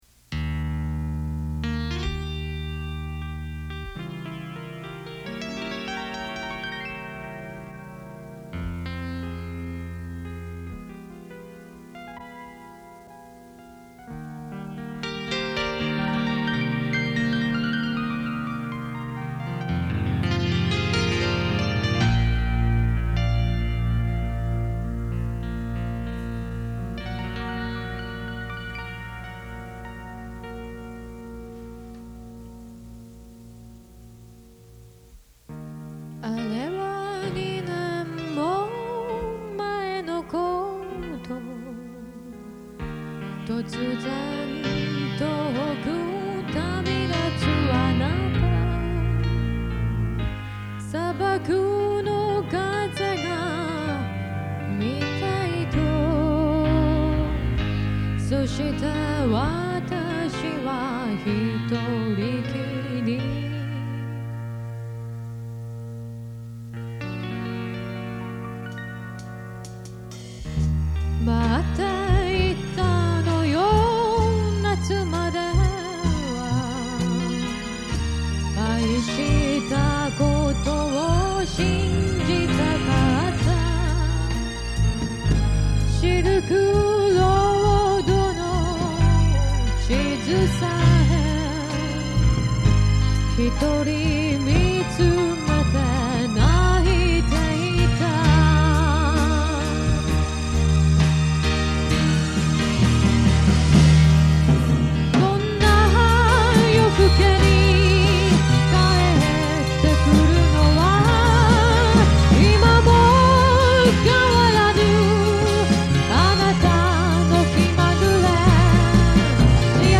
ここで紹介させていただきますのは，もう２０余年前，大学の頃，ひとり軽音楽部の部室で，多重録音で作ったオリジナル曲です。